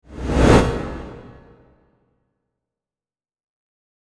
Train Swoosh Sound Effect Free Download
Train Swoosh